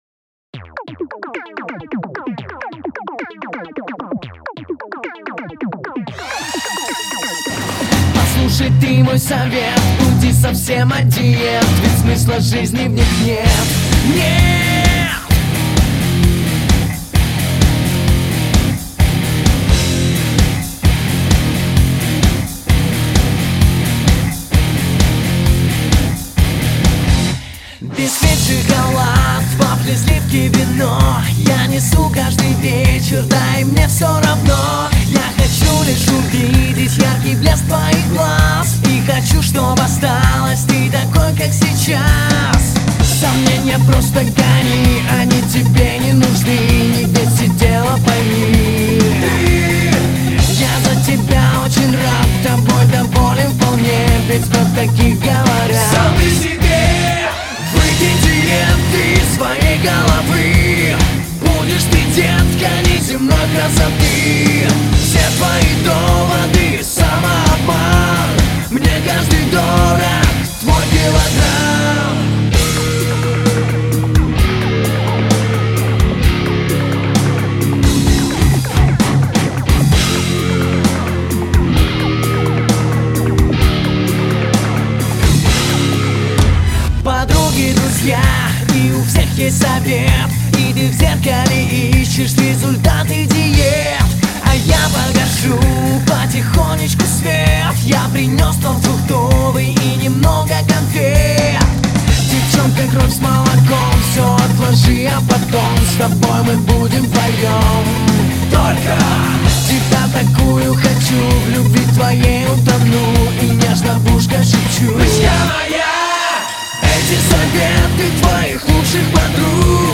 Жанр-рок, русский рок, rock